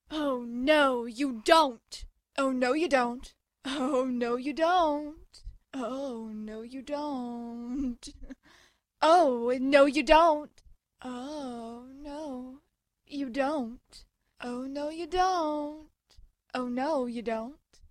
Evil Laugh
evil/sarcastic: Oh no, you don’t